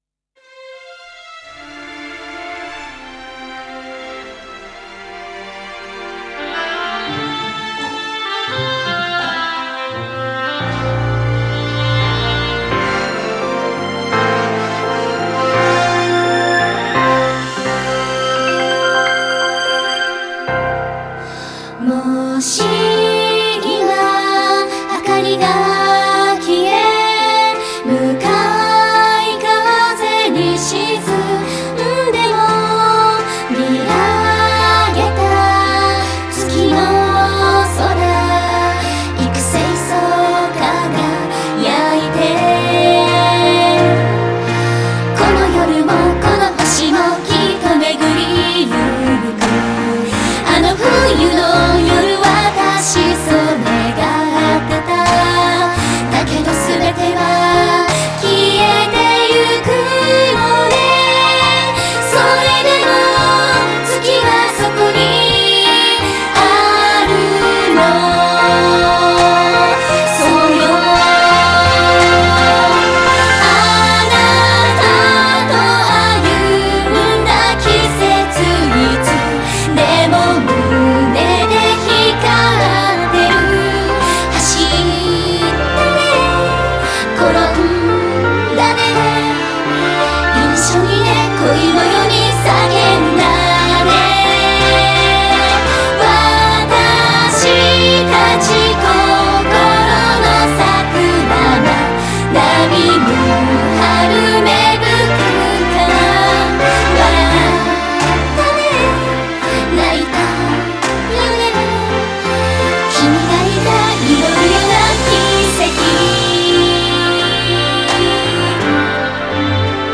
Event ending song. Uses verse 2 of the full OST version.